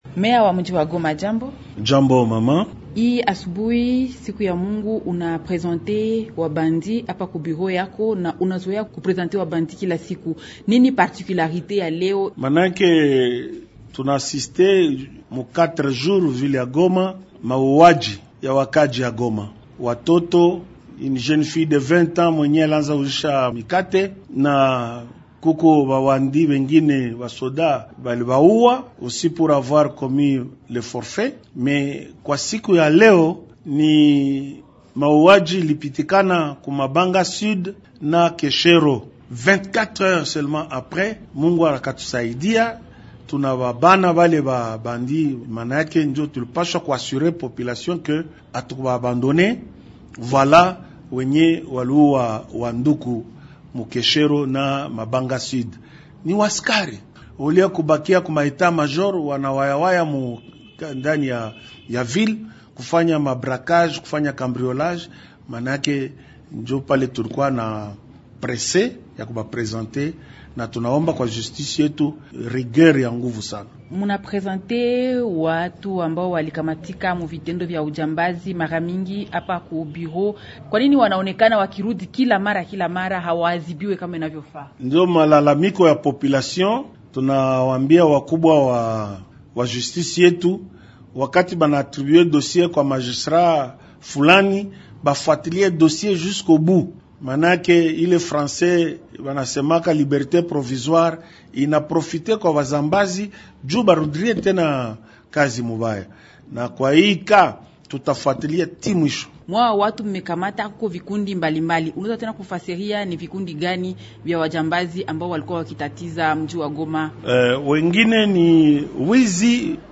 Je, meya anafanya uchambuzi gani kuhusu hali ya usalama katika mjini wa Goma? Kamishama Kapend Kamand Faustin anajibu katika mahojiano haya